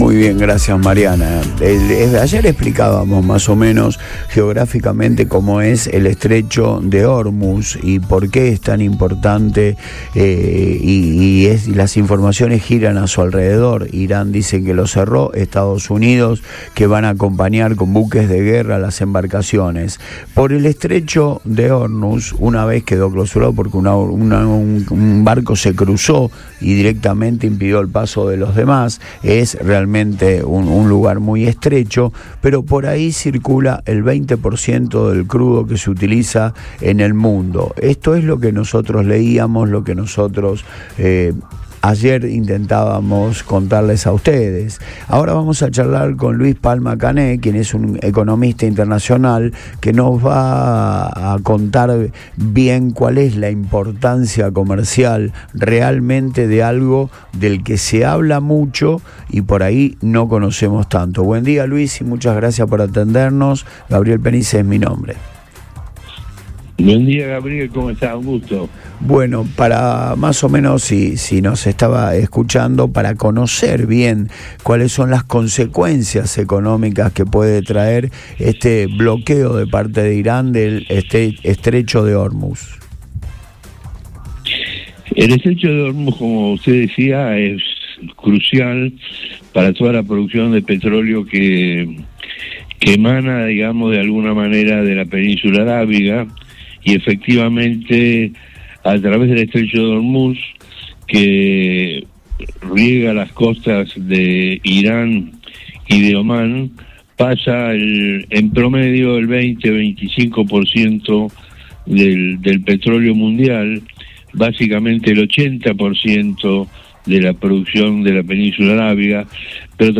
En una charla en “Antes de Todo